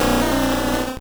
Cri de Lamantine dans Pokémon Or et Argent.